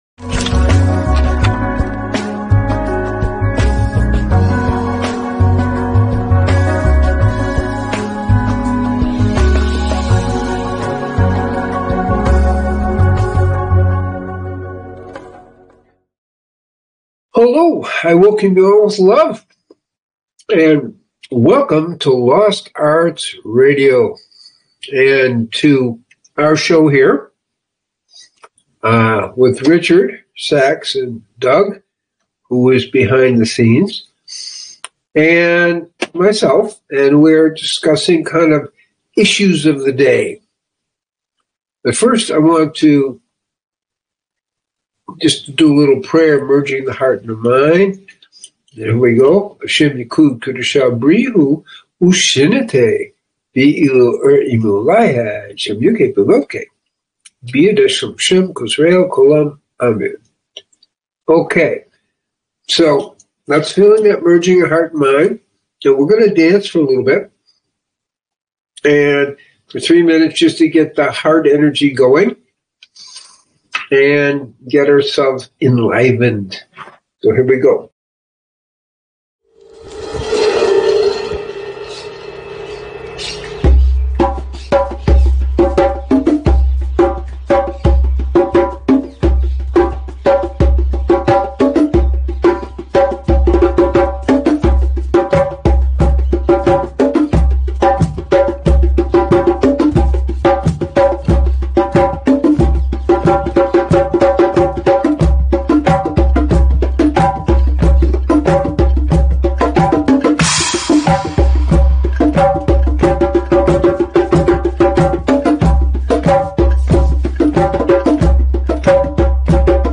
Return To The Truth - Dialogs